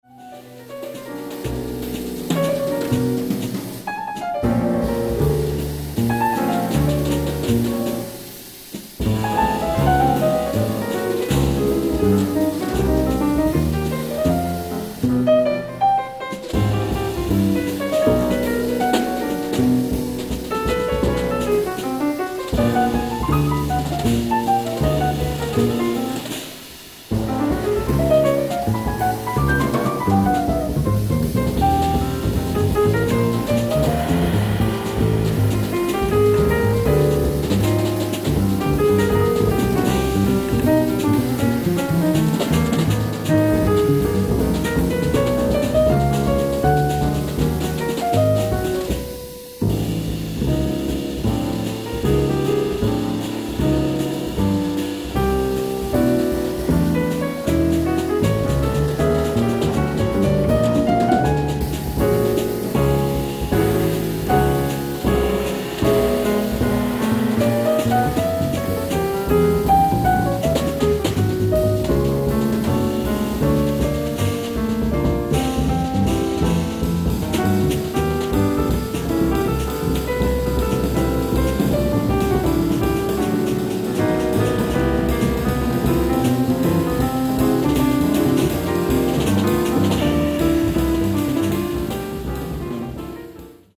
ライブ・アット・ビング・コンサートホール、スタンフォード大学、カリフォルニア 04/02/2025
新トリオによる最新ライブ！！
※試聴用に実際より音質を落としています。